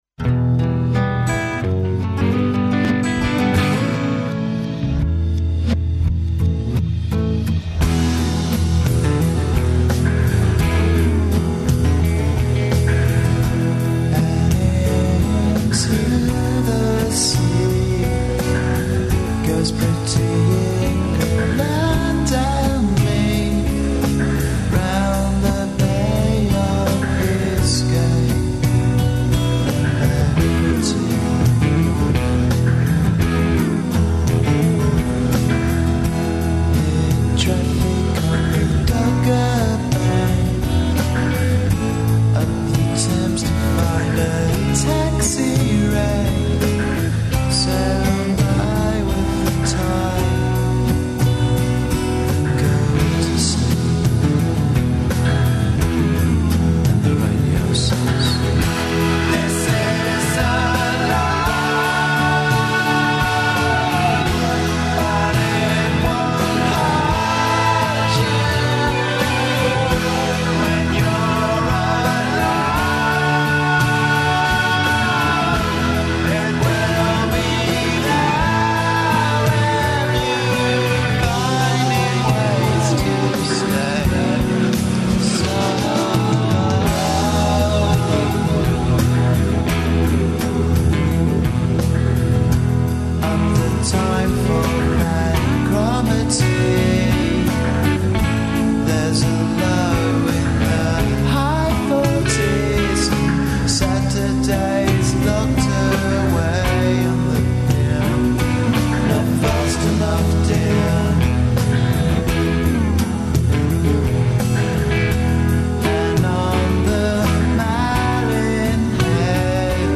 О програму фестивала, на којем ће до 19. априла бити одржано 11 концерата, говоре представници Дома омладине Београда и Културног центра Rex.